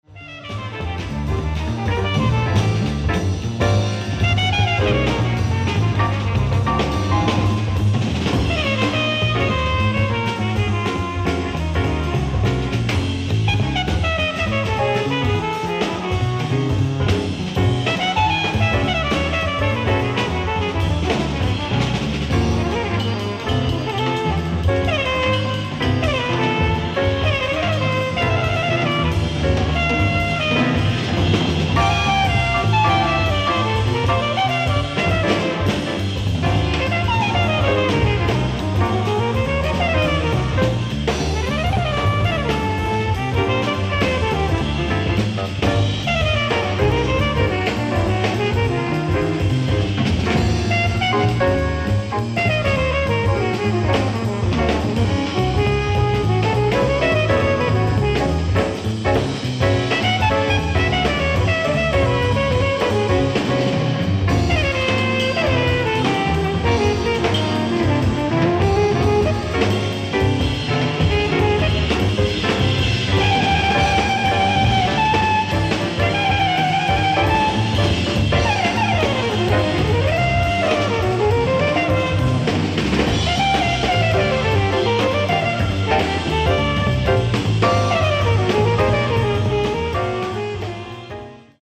ライブ・アット・エンスヘーデ、オランダ 03/29/1985
※試聴用に実際より音質を落としています。